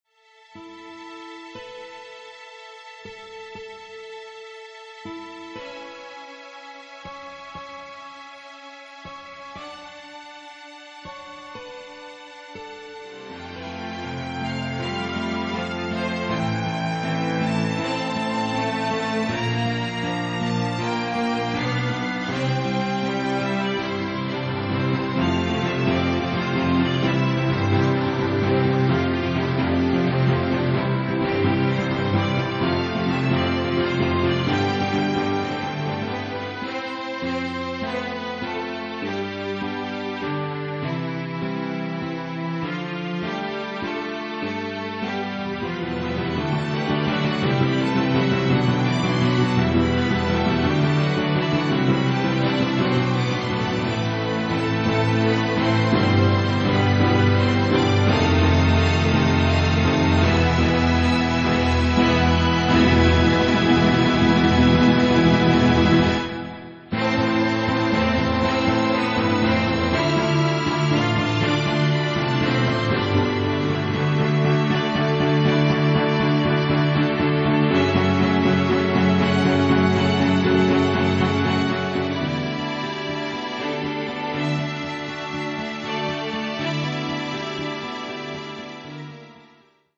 このページでは私が作ったマンドリンオーケストラのオリジナル曲を紹介しています。
＜編成＞　M1/M2/D/C/G/B